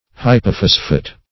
Hypophosphate \Hy`po*phos"phate\, n.
hypophosphate.mp3